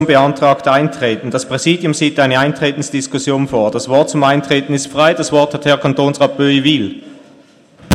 Session des Kantonsrates vom 27. und 28. November 2017